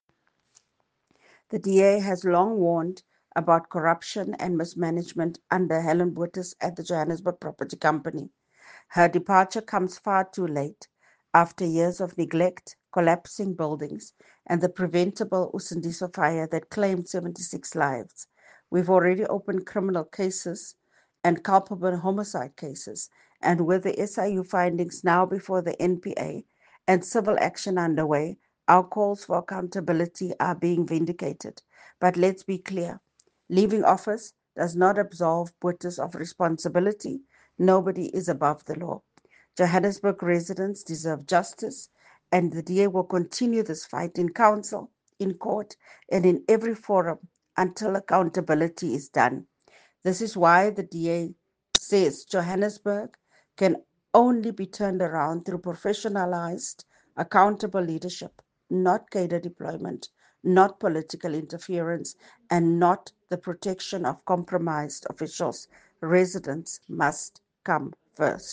Note to Editors: Please find English and Afrikaans soundbites by Cllr Belinda Kayser-Echeozonjoku